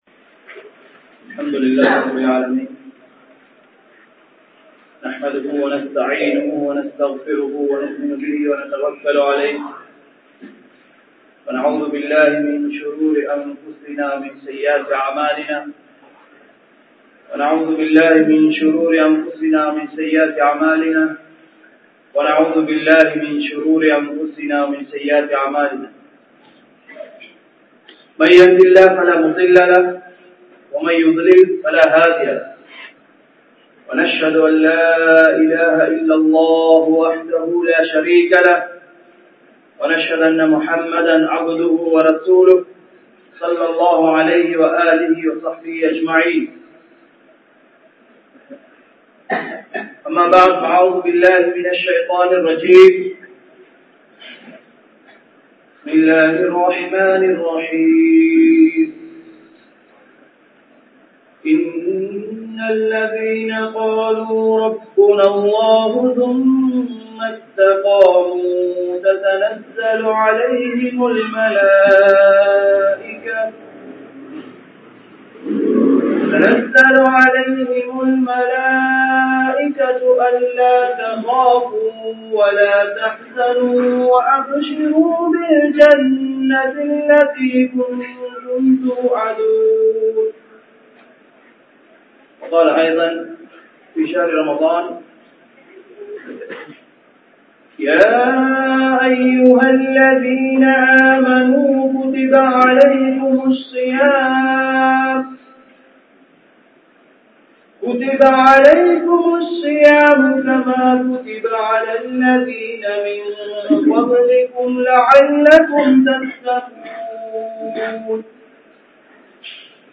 Noanpin Noakkangal (நோன்பின் நோக்கங்கள்) | Audio Bayans | All Ceylon Muslim Youth Community | Addalaichenai